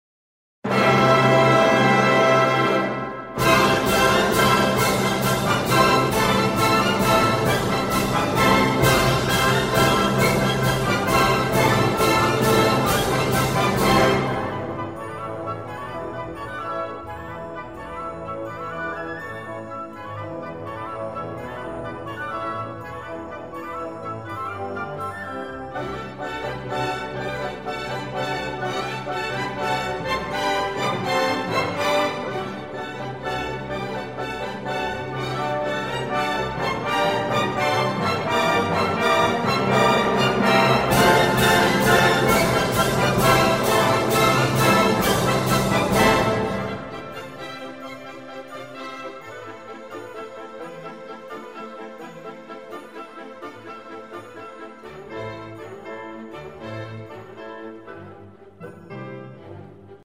Orchestral Works